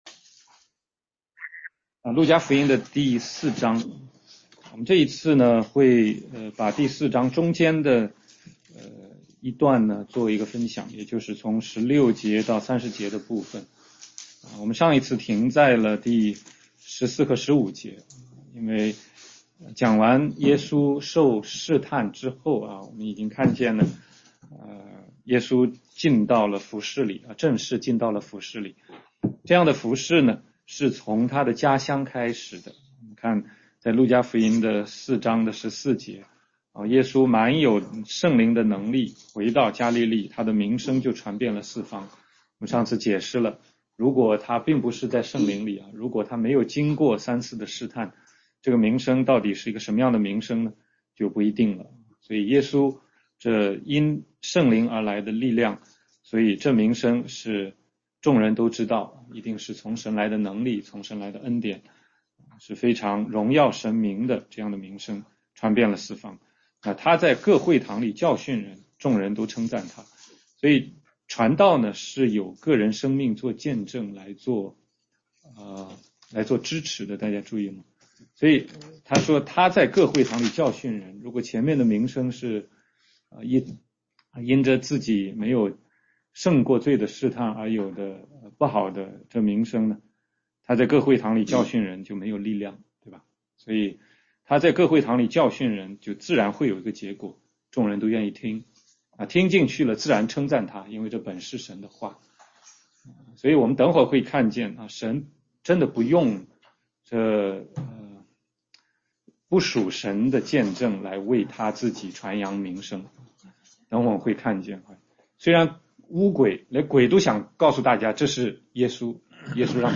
16街讲道录音 - 路加福音4章16-30节
全中文查经